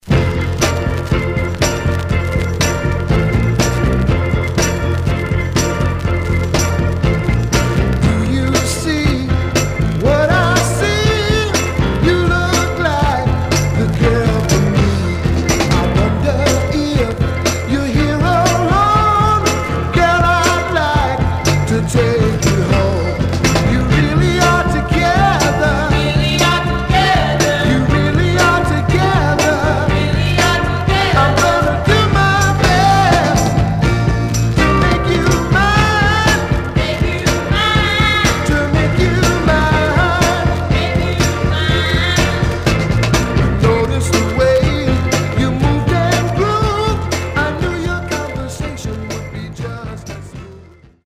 Stereo/mono Mono
Soul